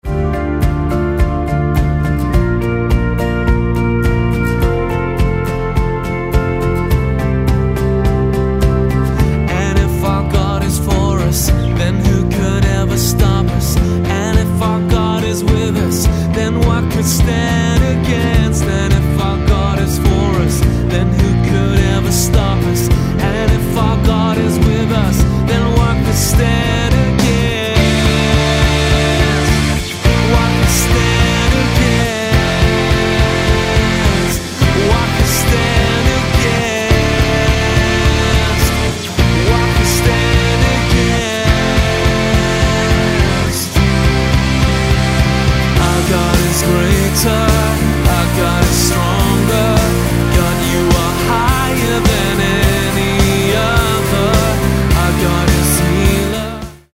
Dm